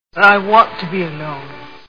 Grand Hotel Movie Sound Bites